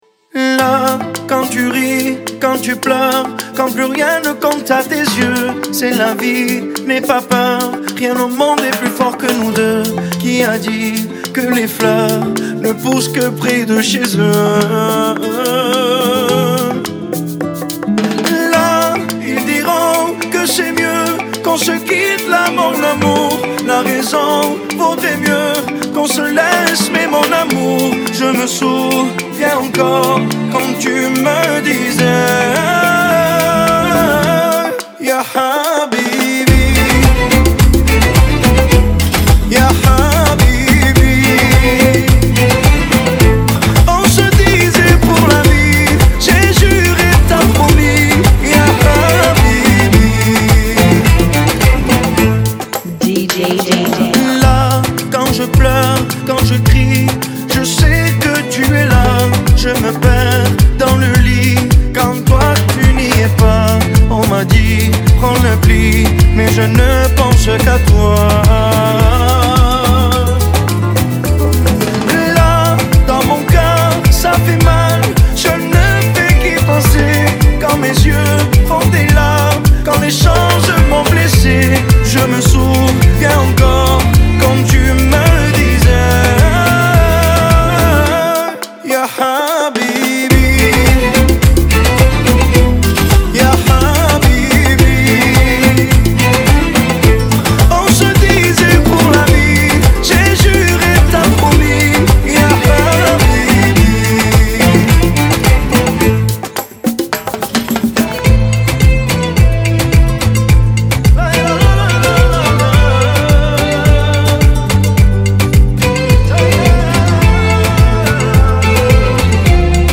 107 BPM
Genre: Salsa Remix